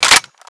PlasticReceiver_Malf.ogg